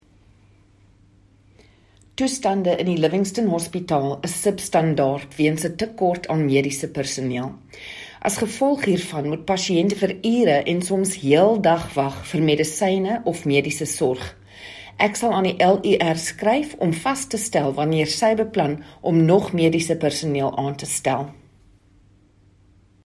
Note to Editors: Please find attached soundbites in